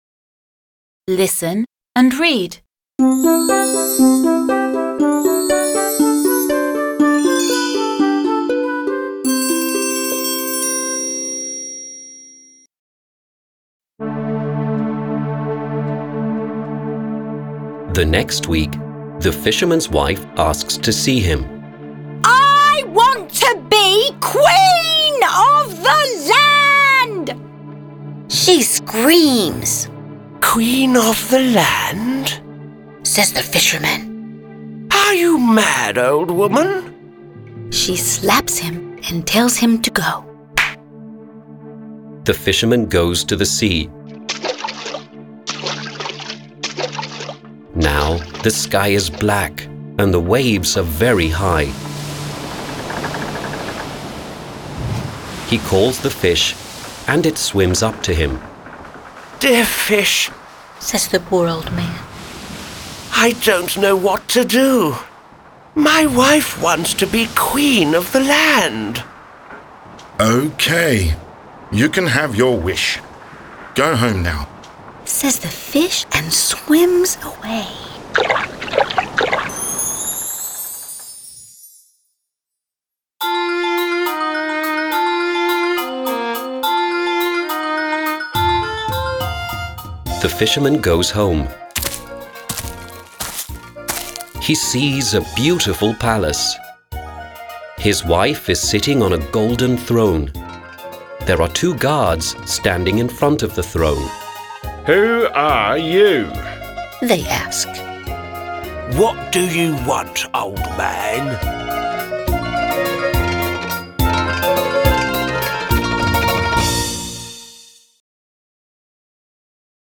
09-Story-p.-22.mp3